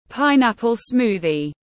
Sinh tố dứa tiếng anh gọi là pineapple smoothie, phiên âm tiếng anh đọc là /ˈpaɪnˌæp.əl ˈsmuː.ði/
Pineapple smoothie /ˈpaɪnˌæp.əl ˈsmuː.ði/